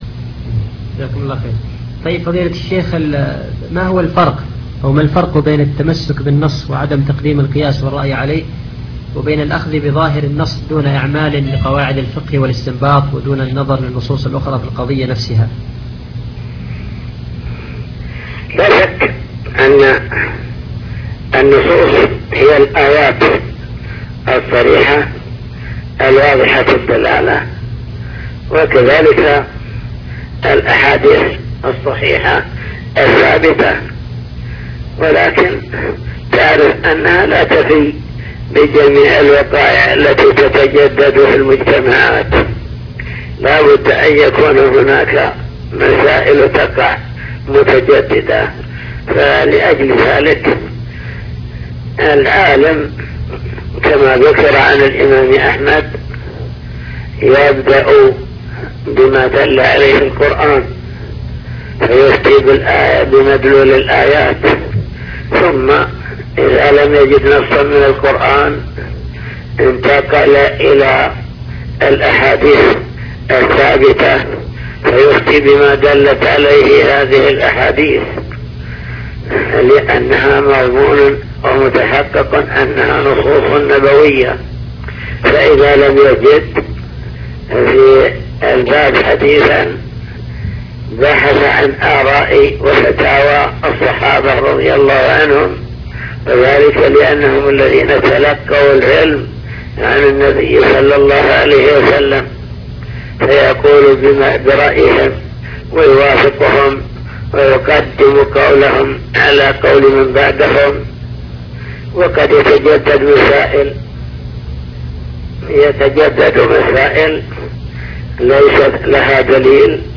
حوار هاتفي